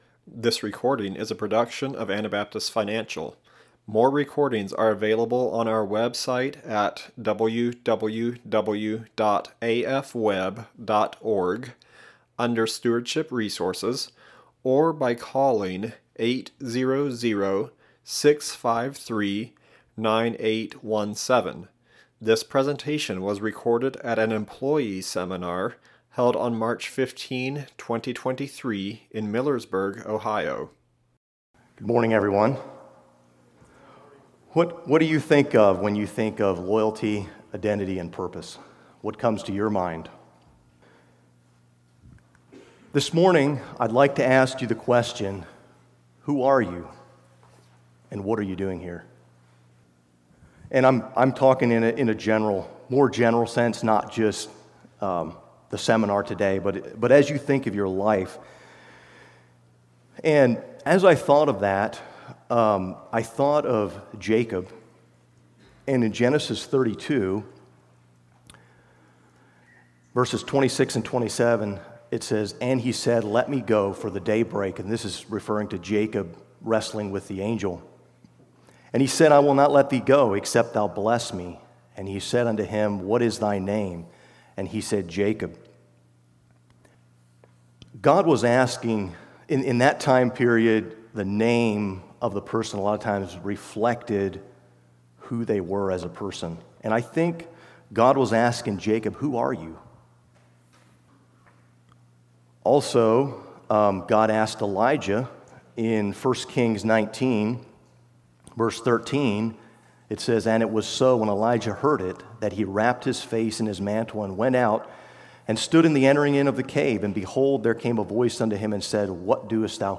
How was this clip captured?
Ohio Employee Seminar 2023